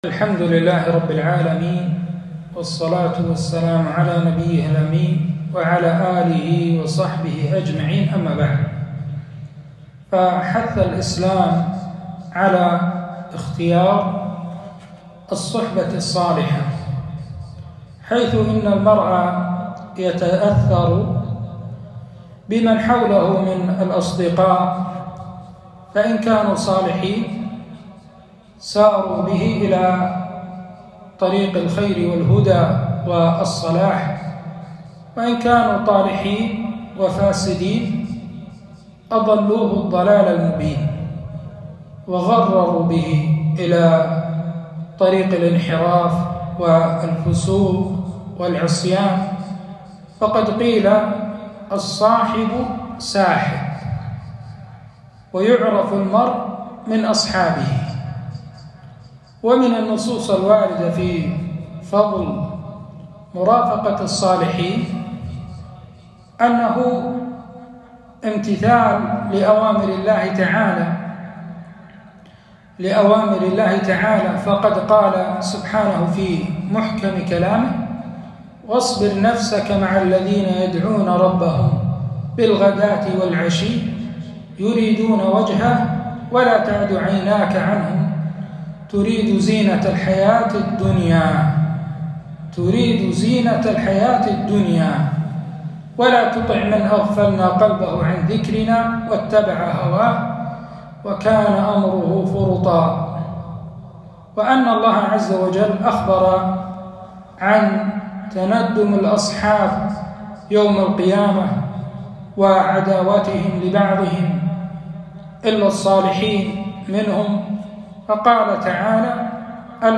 كلمة - الصحبة الصالحة وأثرها في الدنيا والآخرة